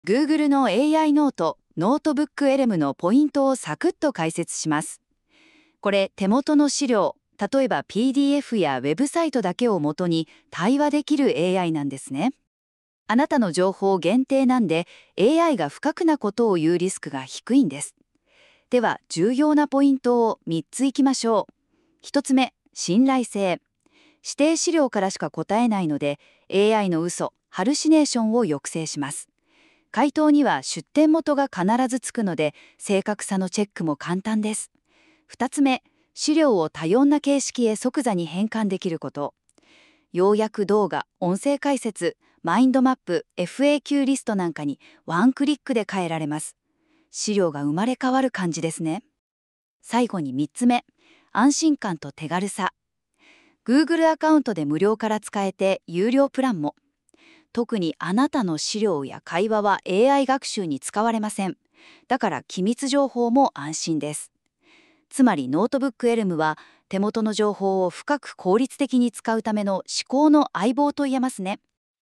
※Google の AI「NotebookLM」で生成した対話形式の音声解説です。